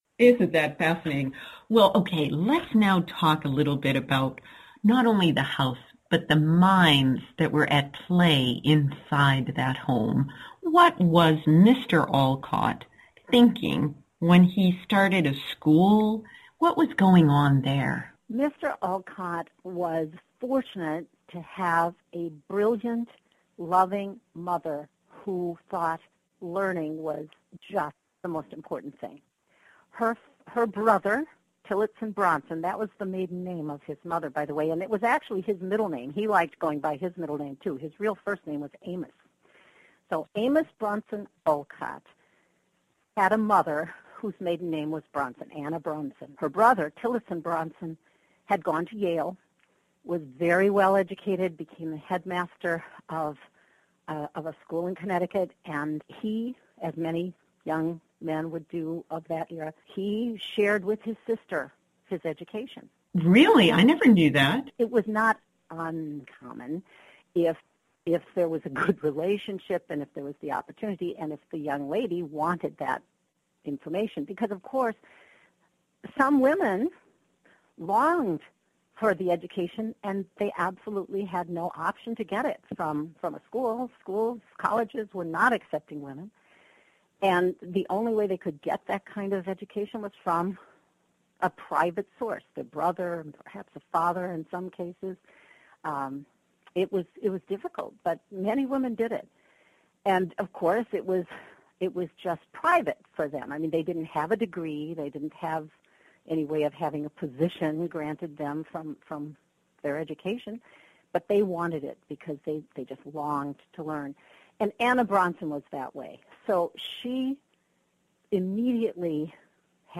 I wish to thank WCOM-FM for granting permission to rebroadcast this interview. It originally aired on October 1st on the Courage Cocktail Radio Show, WCOM LP.